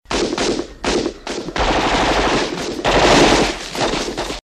• PROGRESSIVE GUNFIRE.mp3
progressive_gunfire_wk6.wav